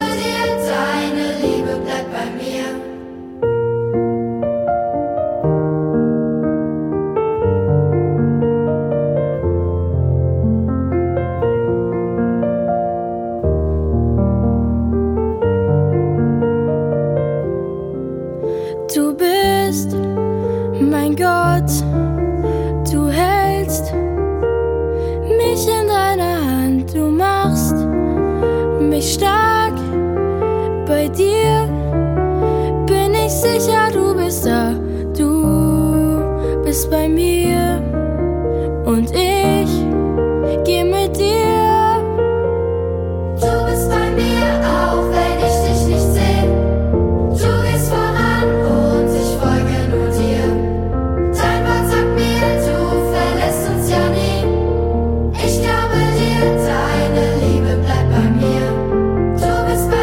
Akustik Version